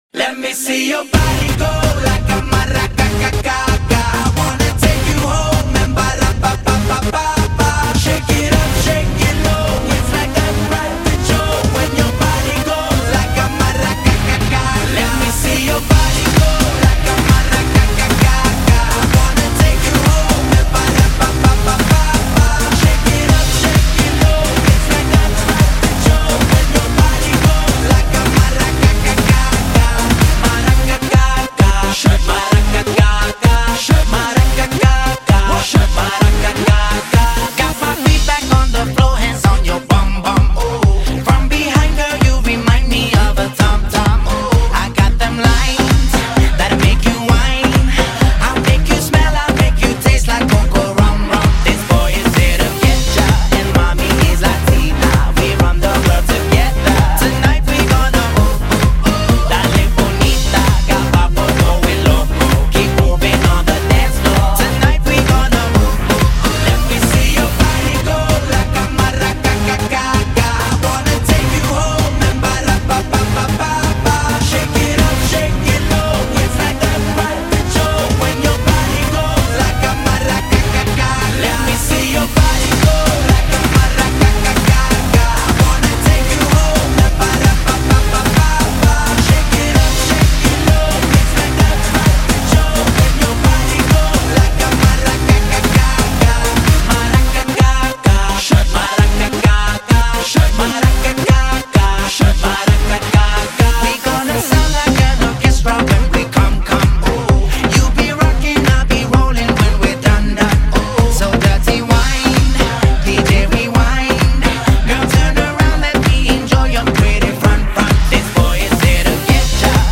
Популярная музыка